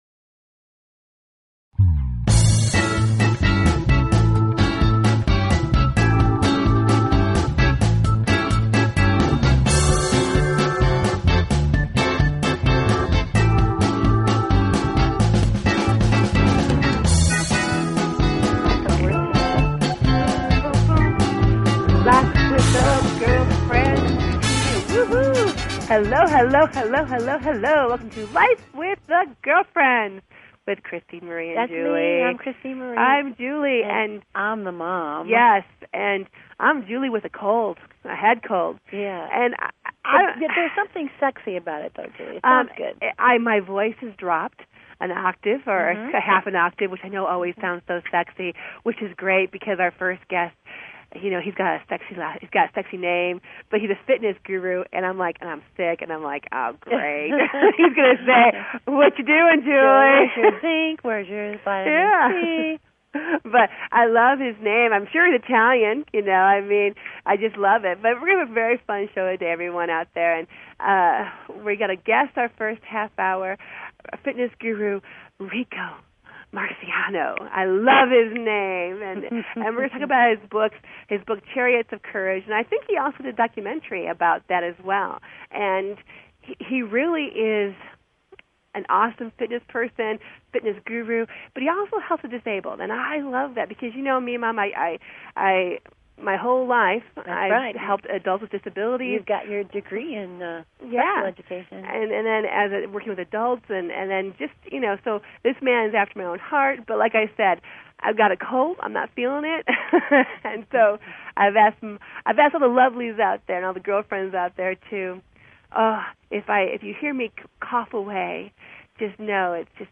Talk Show Episode, Audio Podcast, Life_With_The_Girlfriends and Courtesy of BBS Radio on , show guests , about , categorized as
This mother/daughter coaching duo shares their everyday thoughts on relationships, family, hot topics and current events, and anything that tickles their fancy with warmth, wit, and wisdom. They welcome a wide range of guest to their den for some juicy conversation.